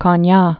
(kôn-yä)